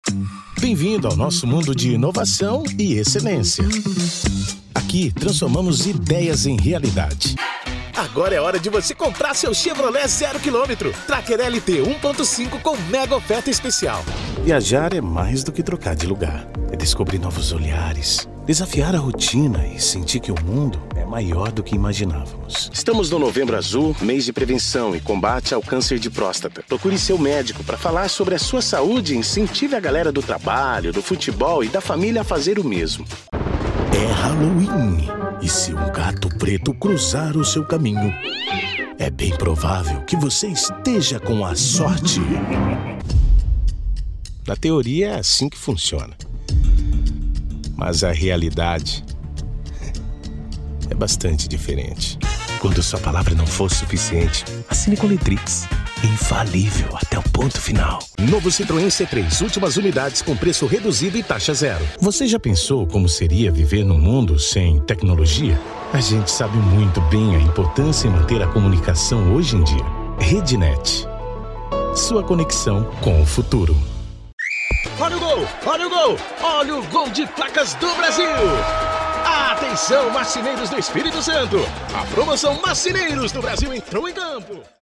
Spot Comercial
Vinhetas
Padrão
Impacto
Animada